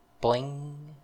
snd_boing.ogg